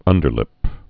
(ŭndər-lĭp)